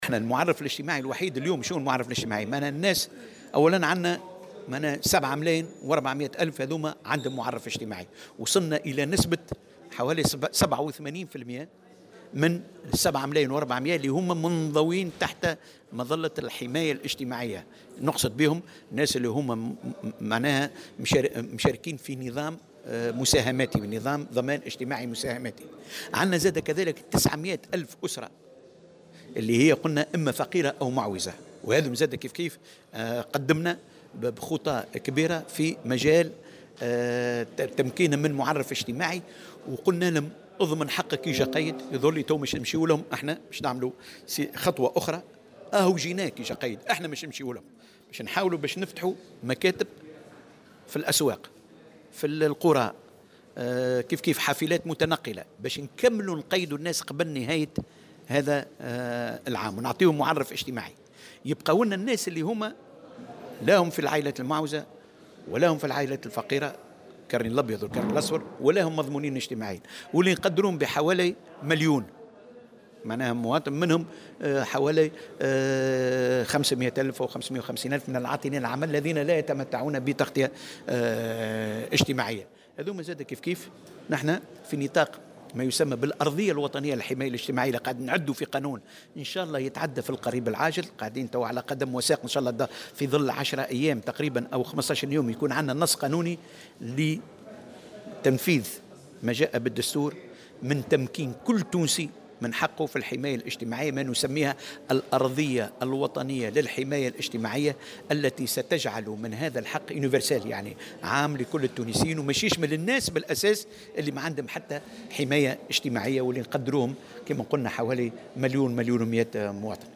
وأضاف في تصريح اليوم لمراسلة "الجوهرة أف أم" على هامش الملتقى الاقليمي حول الخدمة الاجتماعية في منطقة الشرق الاوسط وشمال افريقيا المنعقد بالحمامات، أن 900 ألف أسرة فقيرة ومعوزة تم تمكينها من المعرّف الاجتماعي، مشيرا إلى أنه سيتم فتح مكاتب في الأسواق والقرى وتخصيص حافلات متنقلة لاستكمال تسجيلهم.